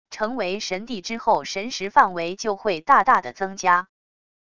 成为神帝之后神识范围就会大大的增加wav音频生成系统WAV Audio Player